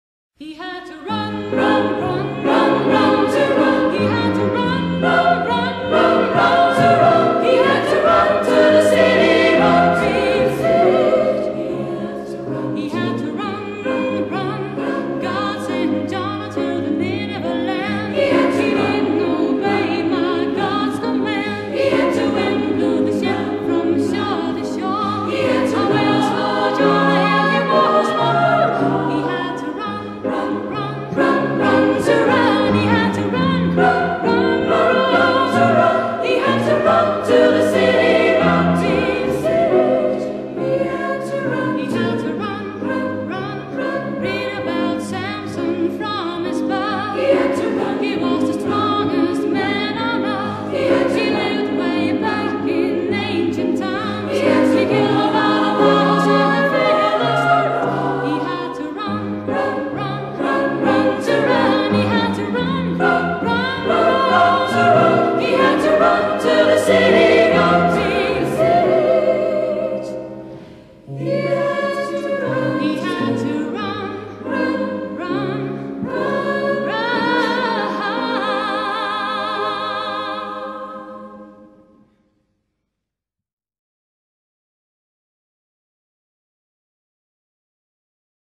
Fuglafjarðar gentukór 1996